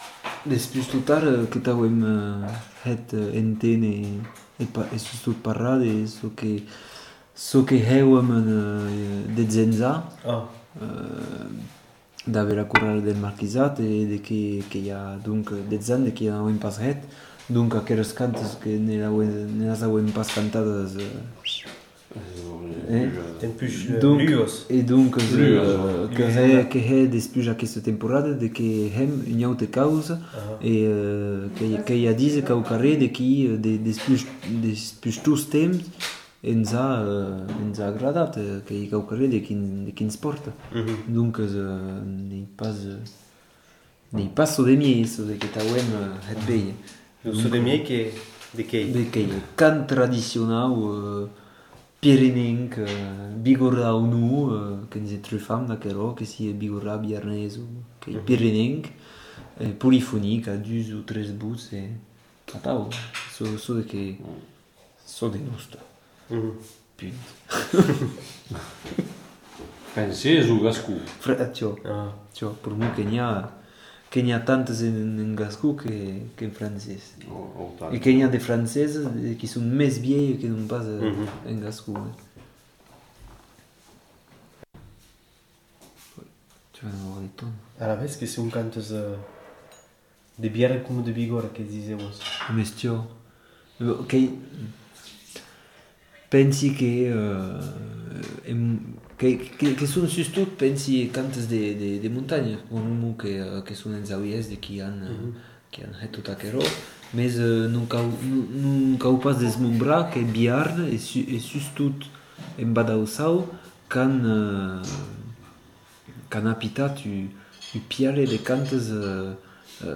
Aire culturelle : Bigorre
Lieu : Bénac
Genre : témoignage thématique